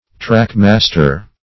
Search Result for " trackmaster" : The Collaborative International Dictionary of English v.0.48: Trackmaster \Track"mas`ter\, n. (Railroad) One who has charge of the track; -- called also roadmaster .